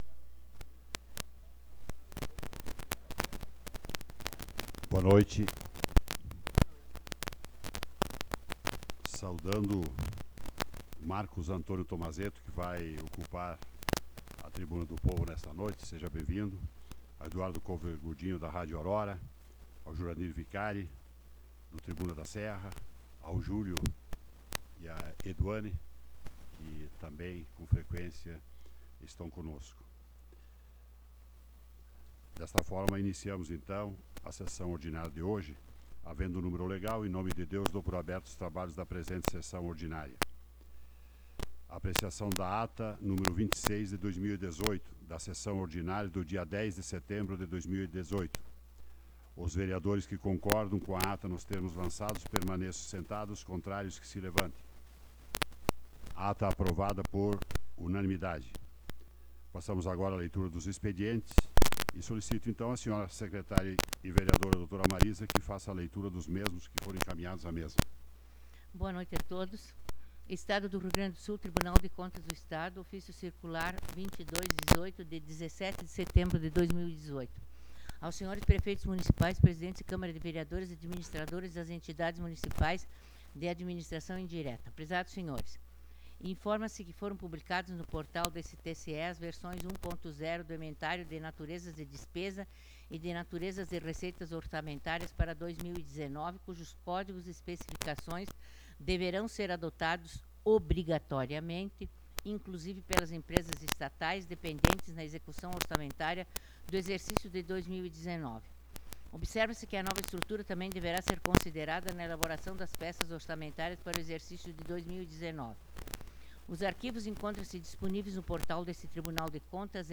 Sessão Ordinária do dia 17 de Setembro de 2018